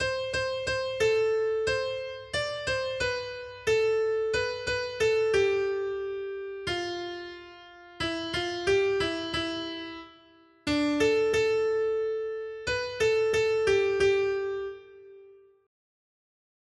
Noty Štítky, zpěvníky ol651.pdf responsoriální žalm Žaltář (Olejník) 651 Skrýt akordy R: Nezapomínej na chudáky, Hospodine! 1.